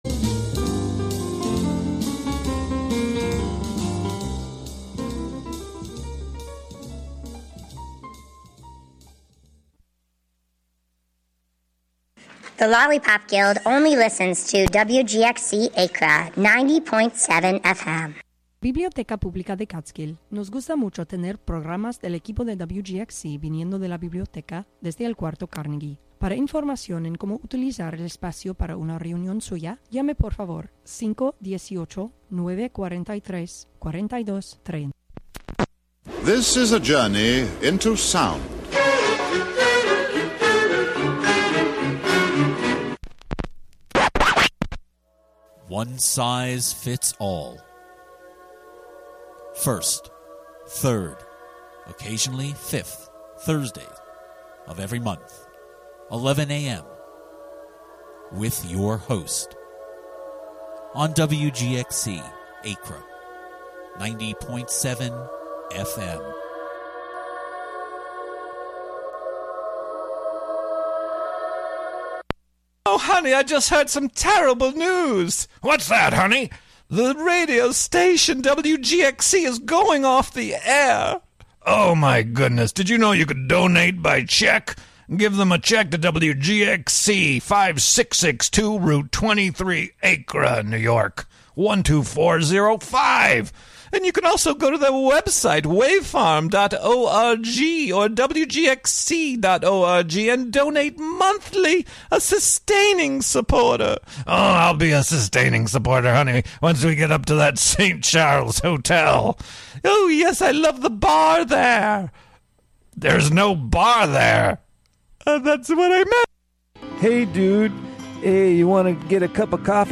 A bi-monthly show serving up rhythm and blues, soul, gospel and funk – the most memorable classics of yesterday, today and tomorrow. Broadcast live from the Carnegie Room of the Catskill Library.